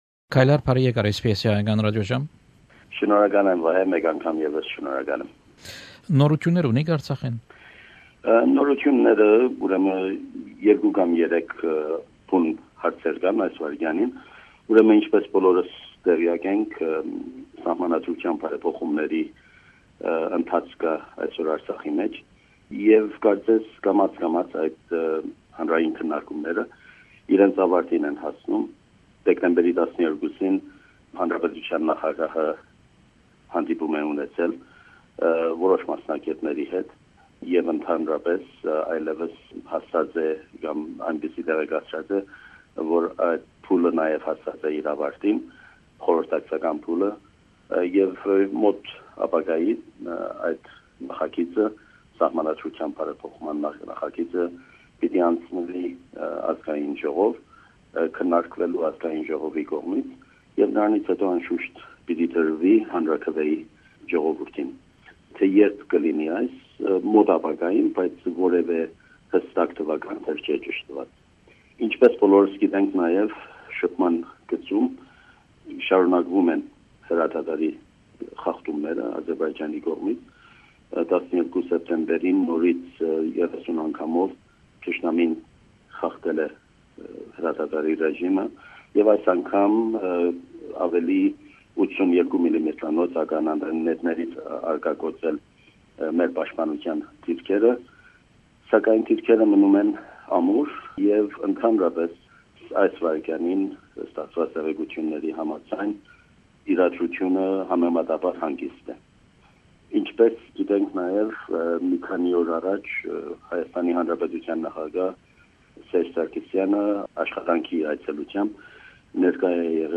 Interview with Kaylar Mikaelian, the permanent representative of NKR in Australia.